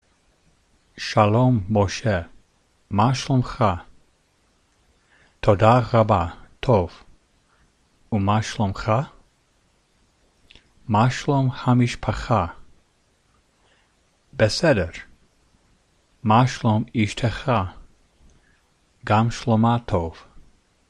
Reading Conversation from How are you?